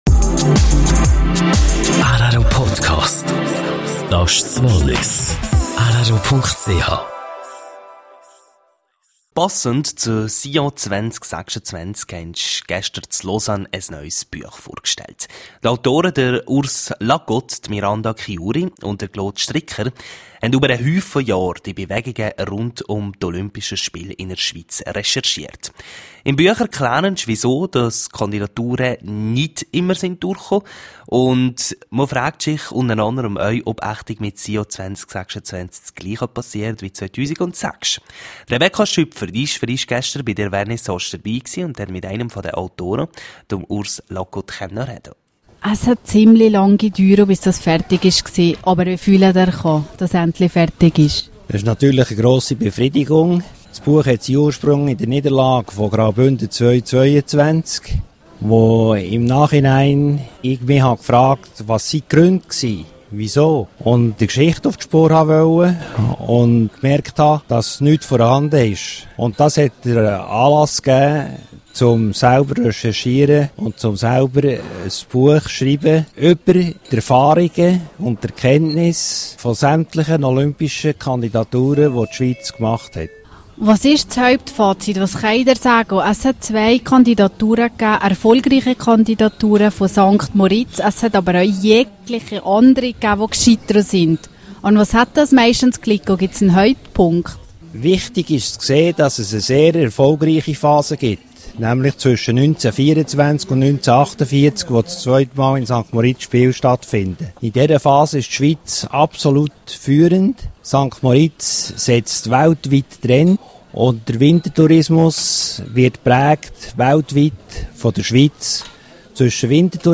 sr Interview mit einem der Autoren